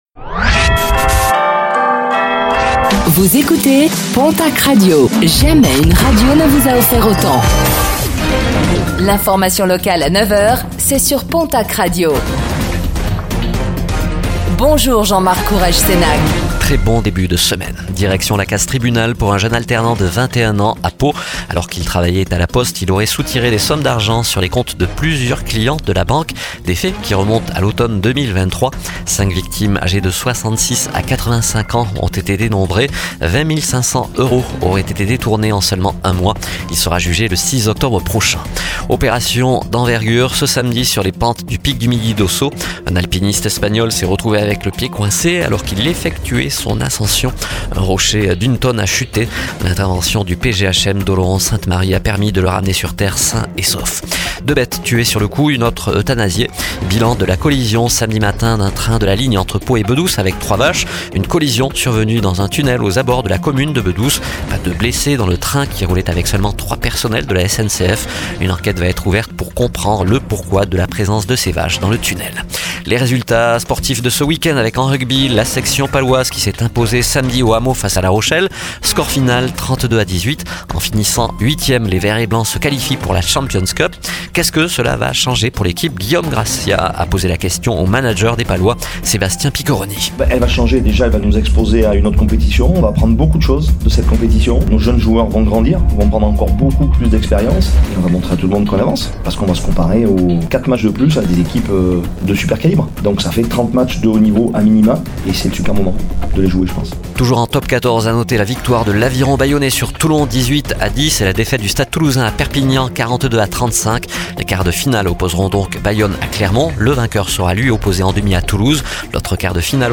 09:05 Écouter le podcast Télécharger le podcast Réécoutez le flash d'information locale de ce lundi 09 juin 2025